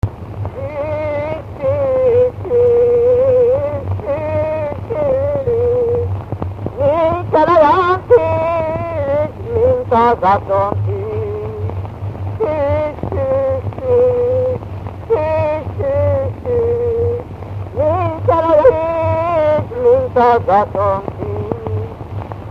Dunántúl - Baranya vm. - Egyházasharaszti
Stílus: 7. Régies kisambitusú dallamok